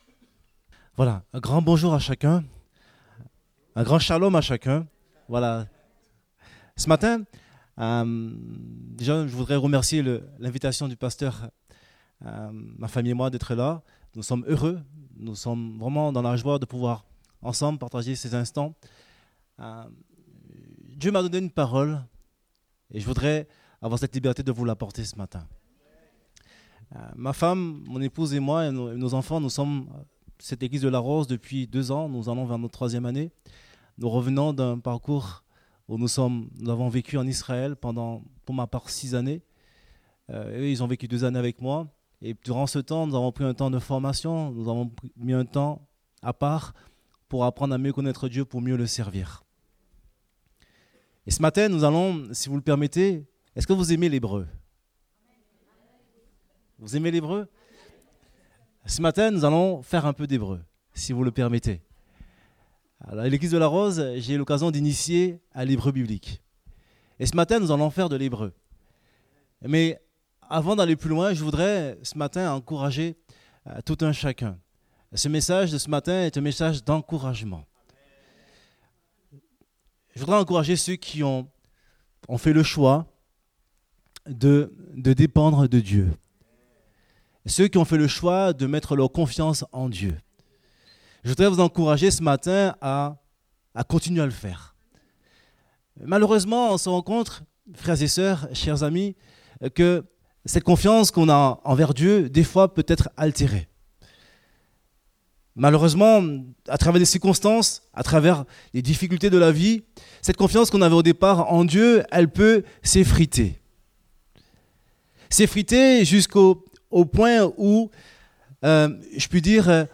Date : 2 décembre 2018 (Culte Dominical)